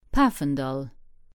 Pfaffenthal (Luxembourgish: Pafendall, pronounced [ˈpaːfəndɑl]